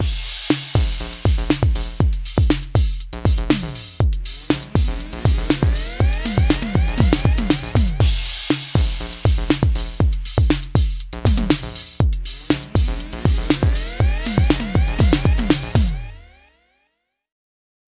The whole thing is tied up by a master compressor placed across the entire drum mix, this adds a little cohesion and punch to the whole kit.
This whole kit being used and fed through the final compressor